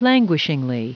Prononciation du mot languishingly en anglais (fichier audio)
Prononciation du mot : languishingly